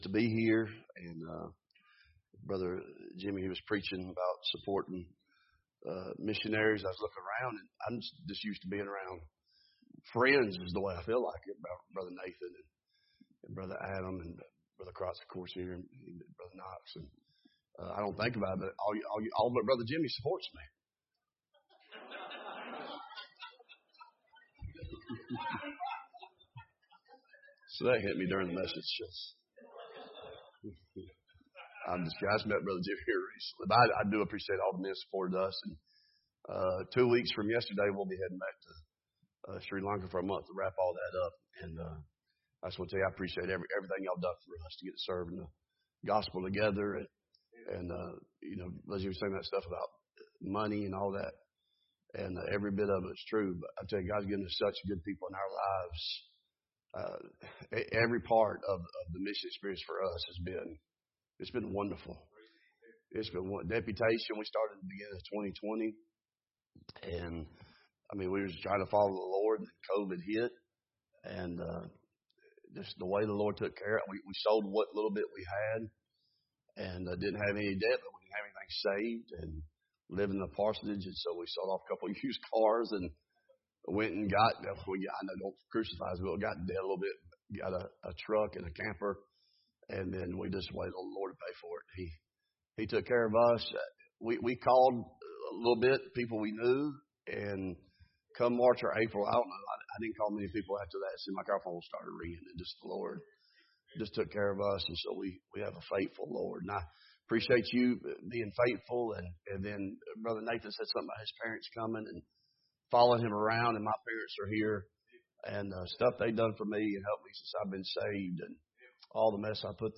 BTBC Bible Conference